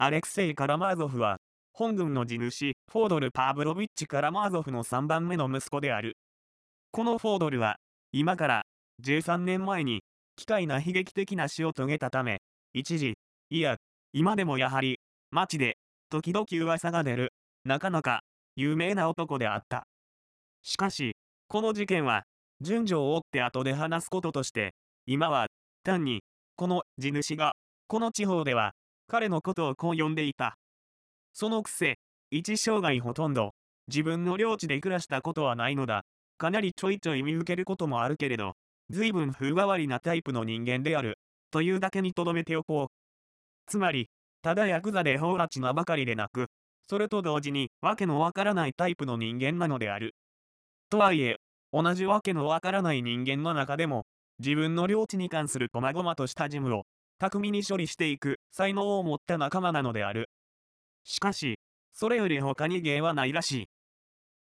電子書籍や、音声朗読機能を使ってみるのも、良さそうなので、試しに作ってみました。
女性の声